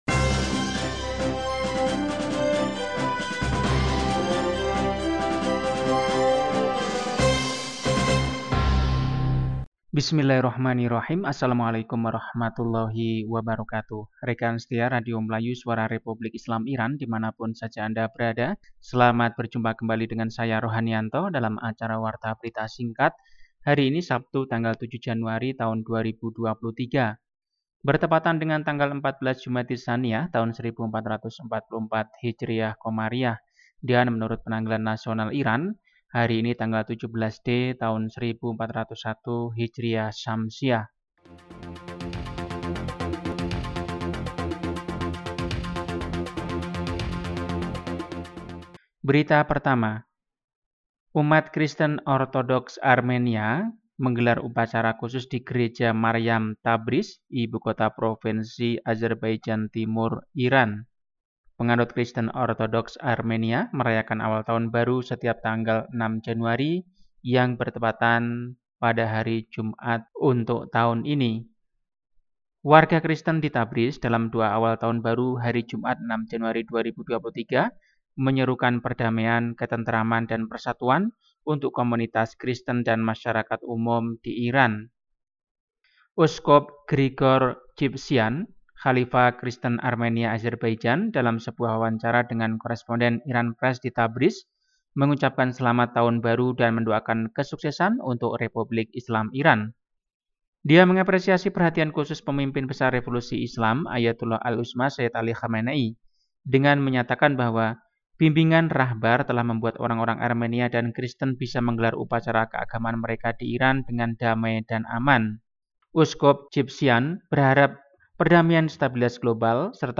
Warta berita hari ini, Sabtu, 7 Januari 2023.